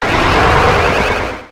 Cri d' Ixon dans Pokémon HOME .